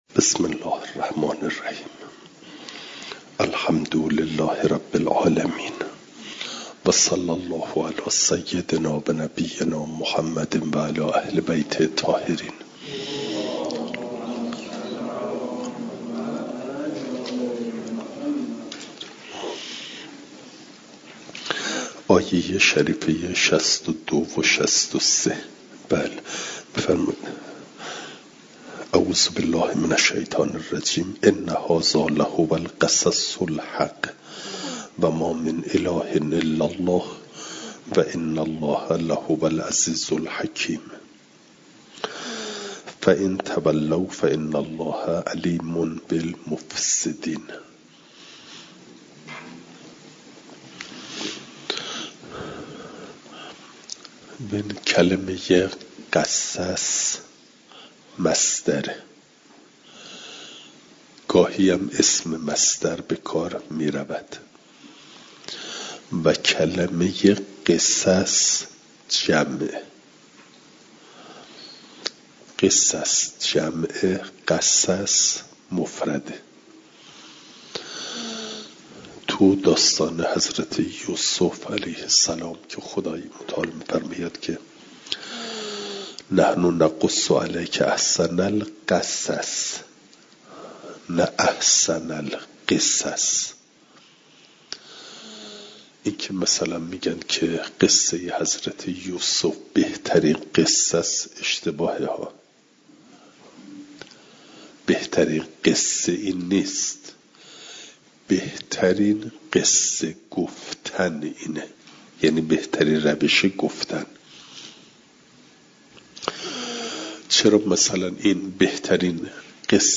فایل صوتی جلسه دویست و هشتادم درس تفسیر مجمع البیان